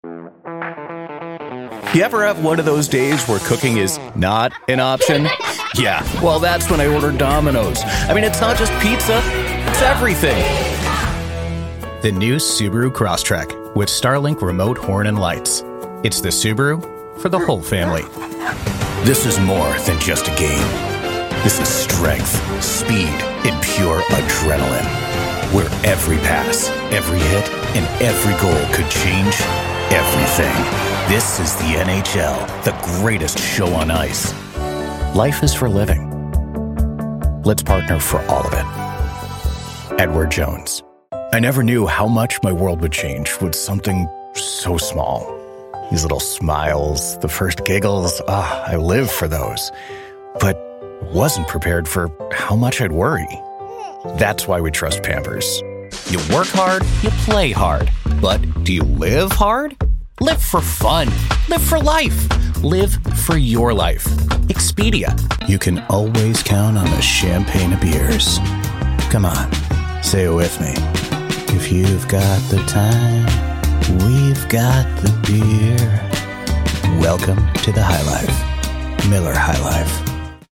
Commercial
Style: Mid-range Millennial — young enough to eat fast food, old enough to give peer-to-peer financial advice, and spot-on for sleepless Dads.
• Warm, confident, casually bright Commercials
Full-service, broadcast-quality home studio in Minneapolis, MN